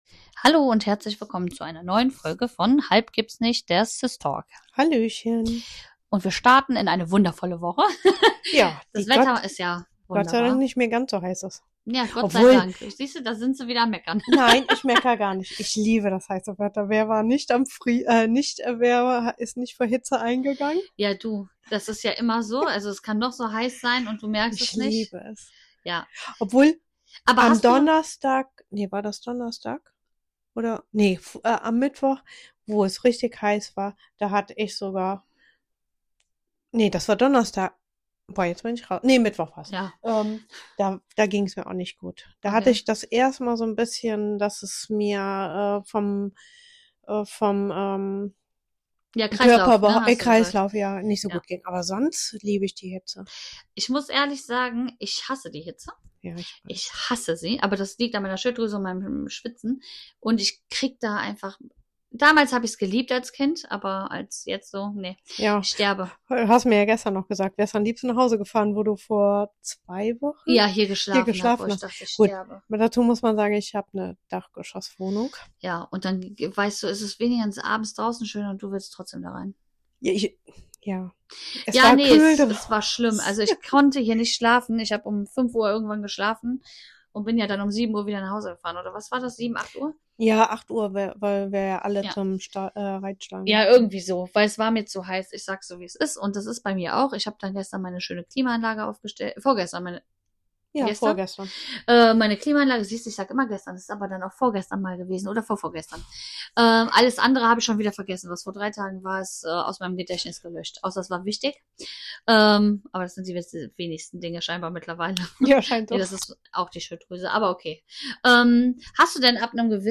Beschreibung vor 7 Monaten Diese Woche geht’s um Zähne zusammenbeißen – im wahrsten Sinne: Die zwei Schwestern sprechen über Angst vor dem Zahnarzt, teilen peinliche (und schmerzhafte) Erlebnisse aus dem Behandlungsstuhl und fragen sich, warum dieser Ort so vielen Menschen Angst macht.